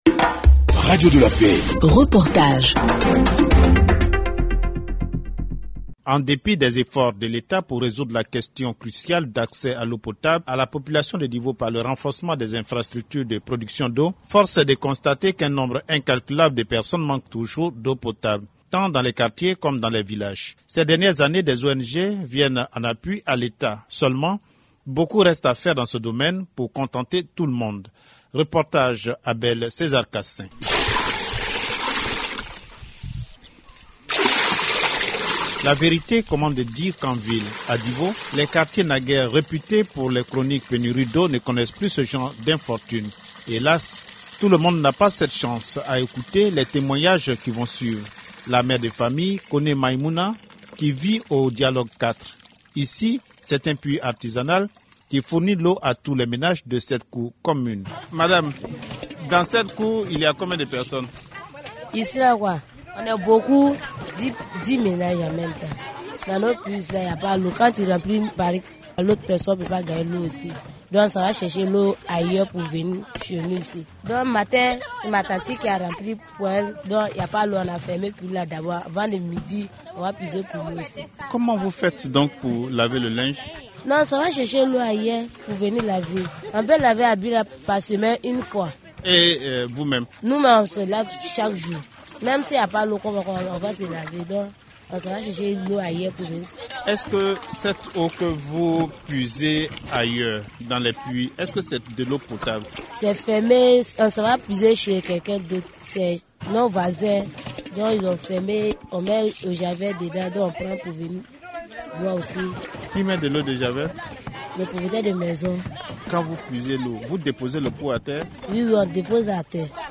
Reportage – Difficile accès à l’eau à Divo - Site Officiel de Radio de la Paix
reportage-difficile-acces-a-leau-a-divo.mp3